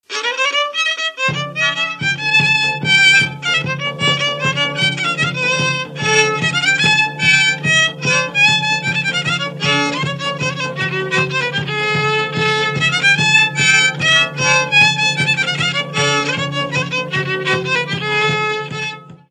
Dallampélda: Hangszeres felvétel
Erdély - Csík vm. - Csíkszentdomokos
hegedű
ütőgardon
Műfaj: Lassú csárdás
Stílus: 3. Pszalmodizáló stílusú dallamok